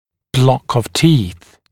[blɔk əv tiːθ][блок ов ти:с]группа зубов